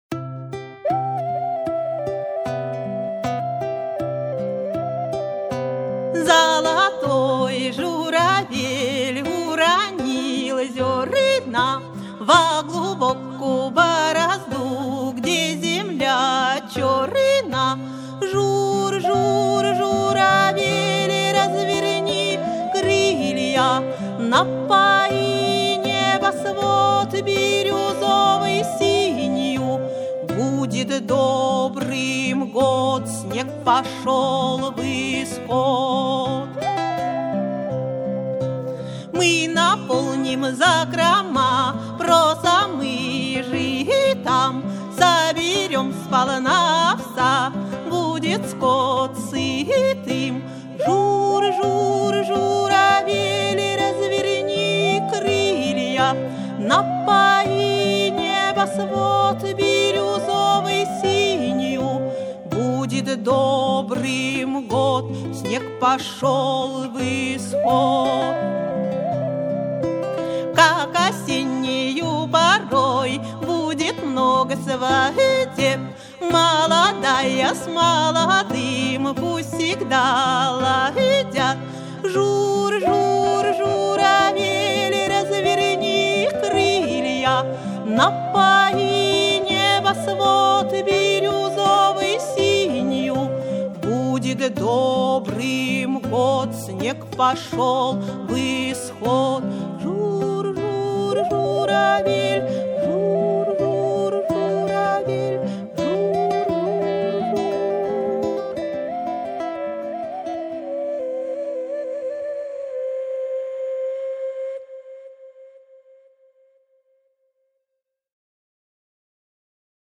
В основном я сочиняю песни в “народном” стиле – заклички, колыбельные, плачи…